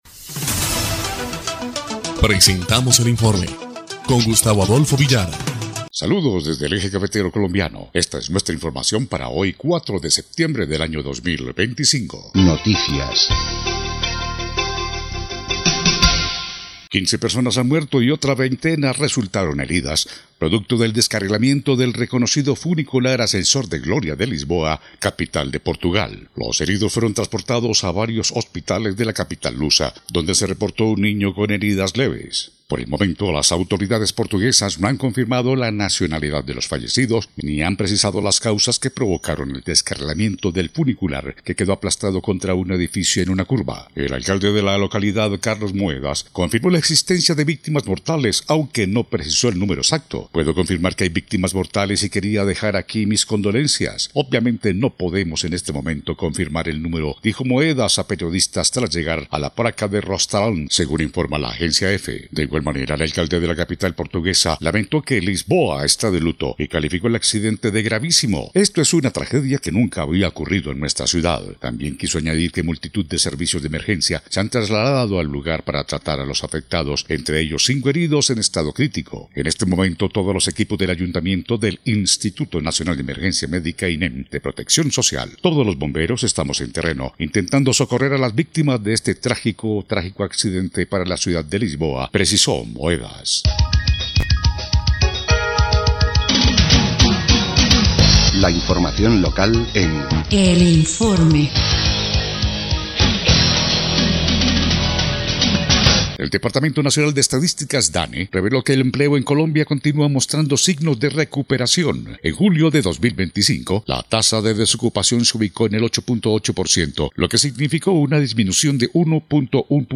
EL INFORME 1° Clip de Noticias del 4 de septiembre de 2025